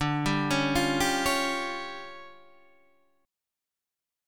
D Minor Major 7th Double Flat 5th